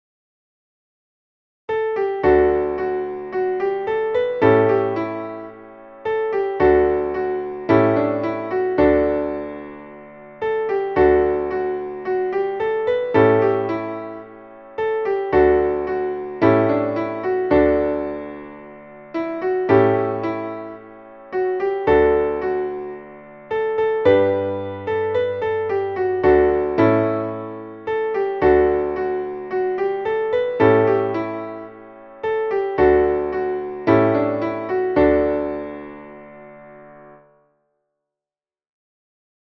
Traditionelles Abend-/Schlaf-/Wiegenlied